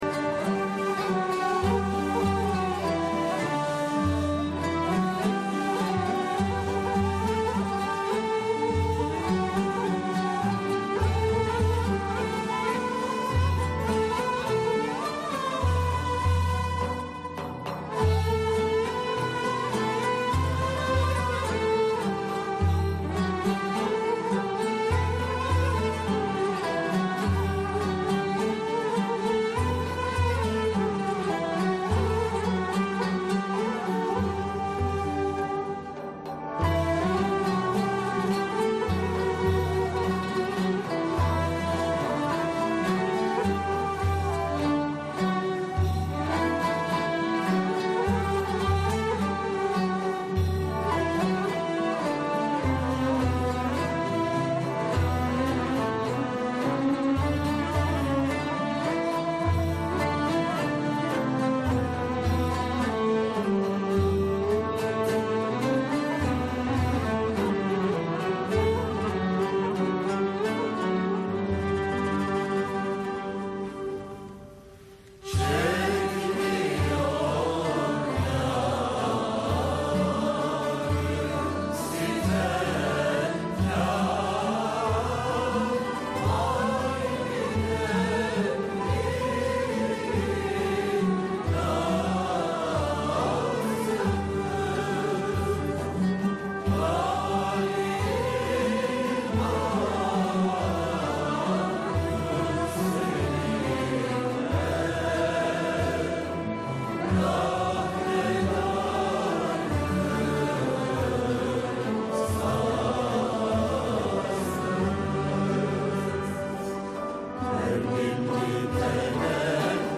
Makam: Acem Aşiran
Usûl: Yürük Semai